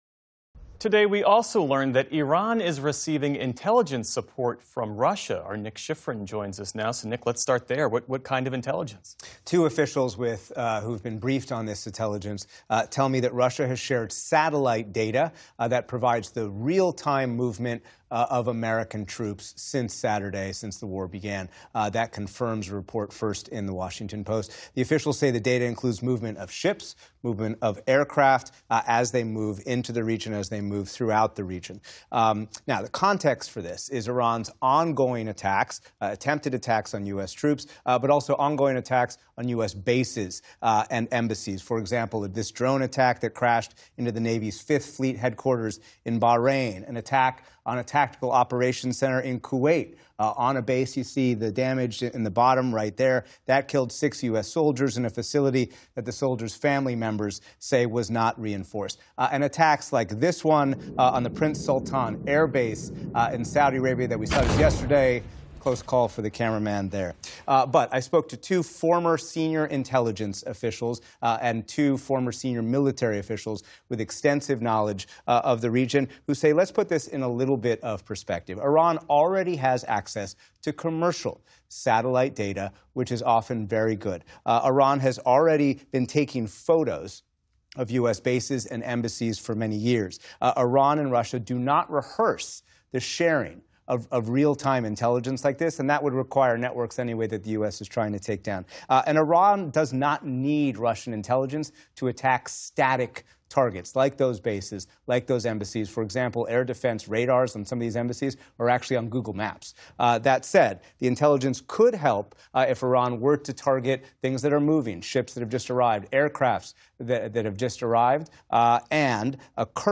Iran is receiving intelligence support from Russia, according to two U.S. officials who spoke with PBS News. Nick Schifrin reports.